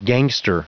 Prononciation du mot gangster en anglais (fichier audio)
Prononciation du mot : gangster